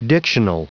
Prononciation du mot dictional en anglais (fichier audio)
Prononciation du mot : dictional